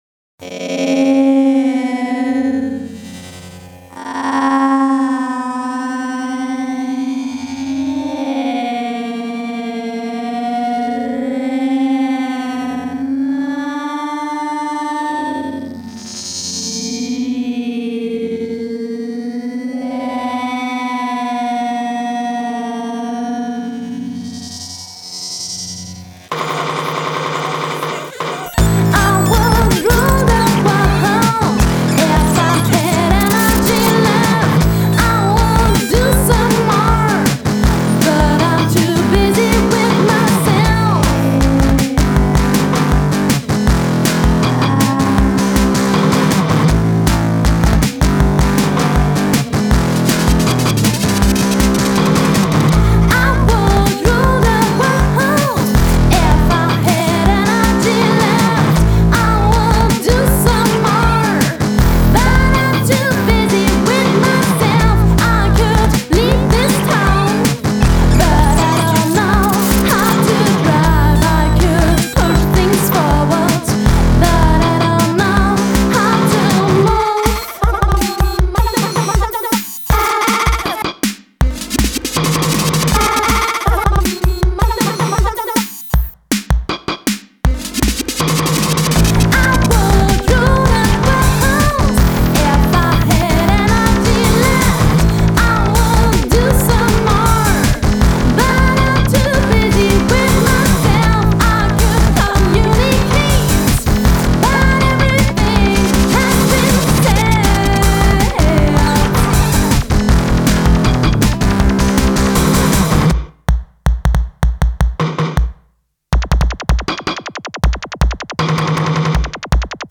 Electronic Music Solo Project since 2003